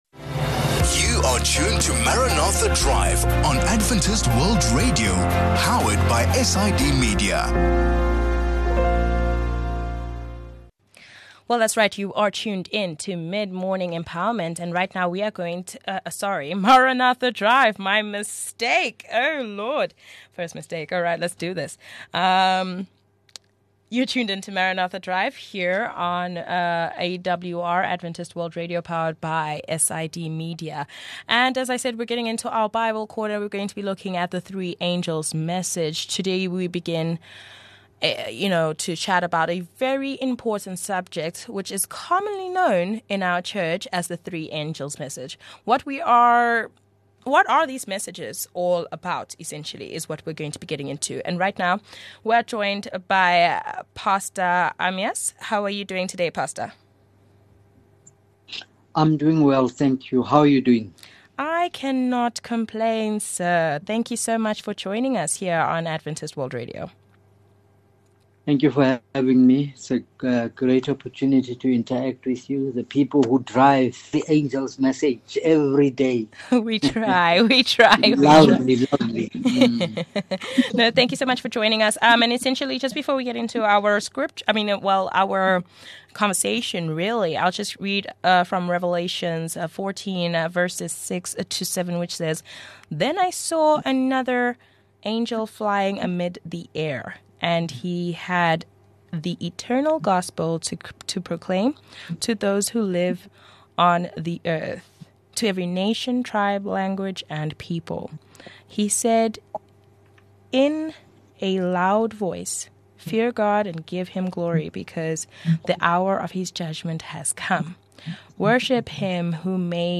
This is the introductory conversation on what is commonly referred to as the Three Angels’ Messages.